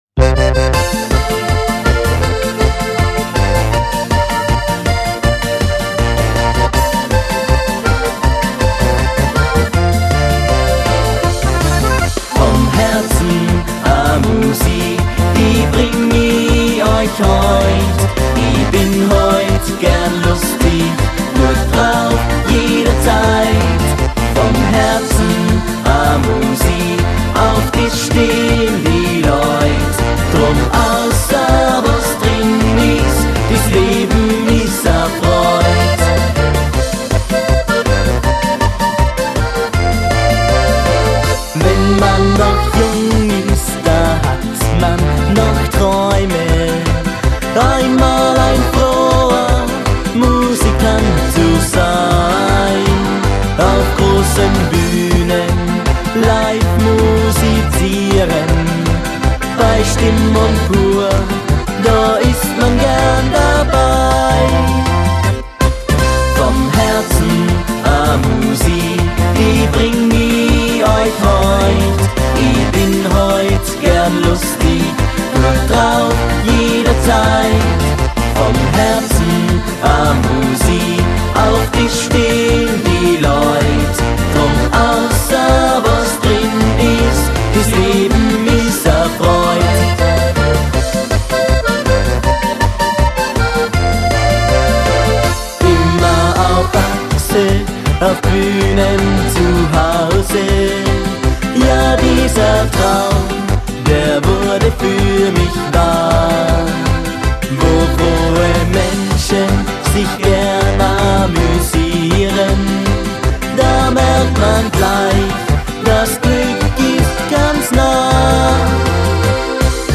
Volksmusik/Schlager
- Kurz gesagt, ein junger talentierter Alleinunterhalter mit umfangreichen volkstümlichen Repertoire und Stimmungsgarantie!